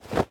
Equip_leather1.ogg.mp3